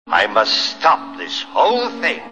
Program Error(18K)
grinch_program_error.wav